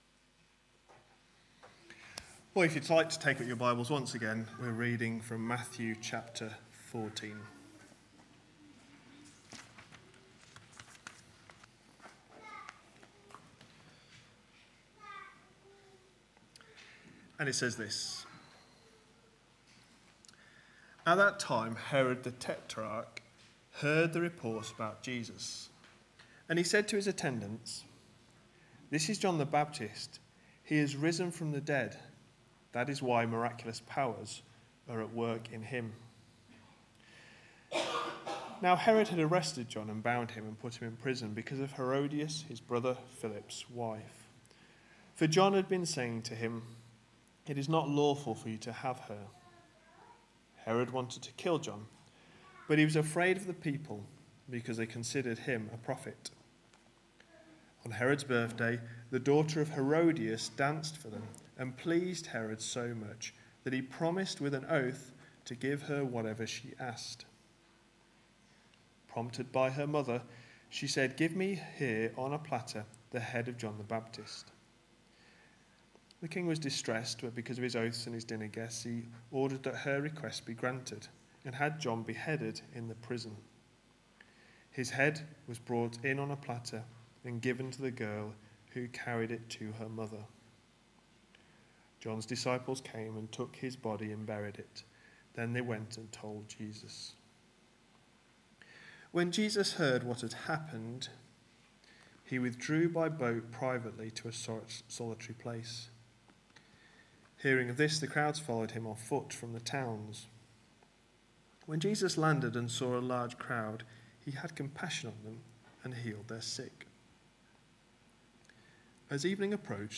A sermon preached on 29th November, 2015, as part of our Matthew series.